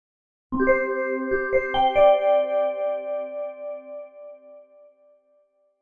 rzhd_-_zvuk_obyavleniya_zwooc.mp3